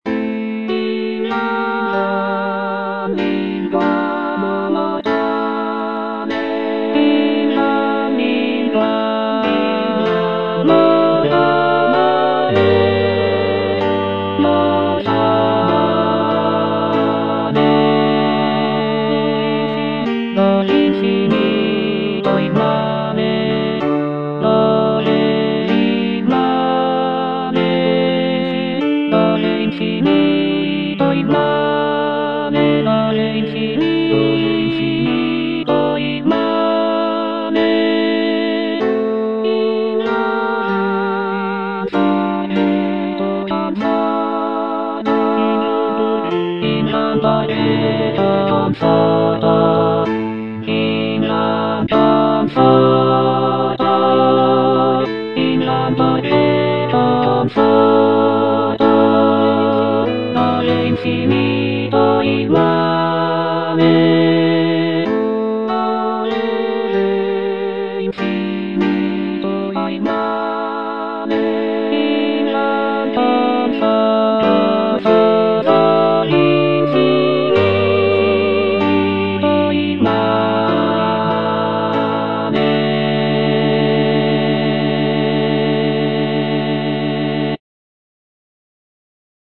C. MONTEVERDI - LAMENTO D'ARIANNA (VERSION 2) Coro I: Invan lingua mortale - Alto (Emphasised voice and other voices) Ads stop: auto-stop
The piece is based on the character of Ariadne from Greek mythology, who is abandoned by her lover Theseus on the island of Naxos. The music is characterized by its expressive melodies and poignant harmonies, making it a powerful and moving example of early Baroque vocal music.